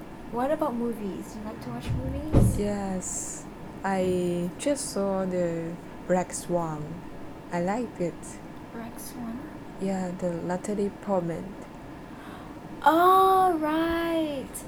S1 = Brunei female S2 = Chinese female
S2: yeah the natalie portman S1: OH: RIGHT: Intended Words: Black Swan Heard as: Rex One Discussion: There is [r] instead of [l] in black . Even in the context of movies, S1 could not understand it until S2 added that Natlie Portman was in it.